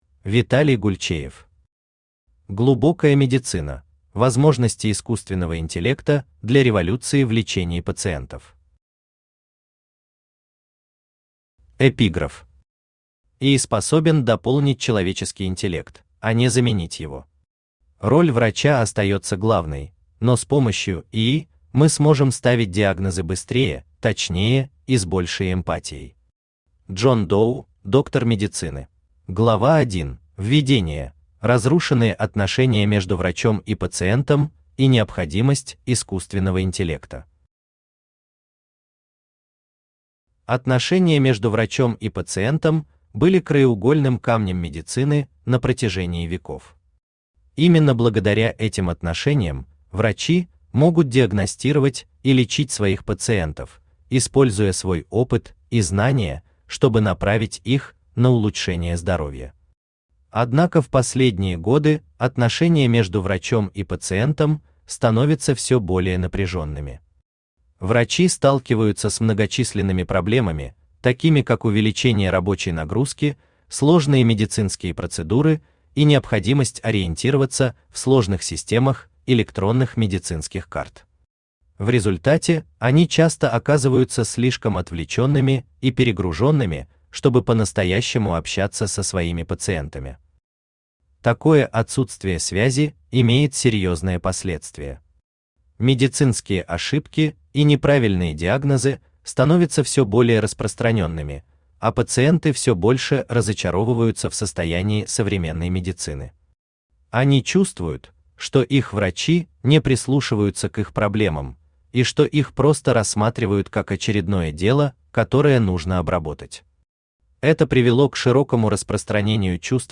Аудиокнига Глубокая медицина: возможности искусственного интеллекта для революции в лечении пациентов | Библиотека аудиокниг
Читает аудиокнигу Авточтец ЛитРес.